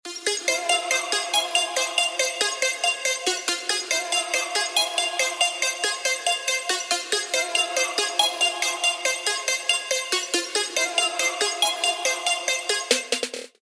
• Качество: 320, Stereo
Electronic
без слов
Trap
страшные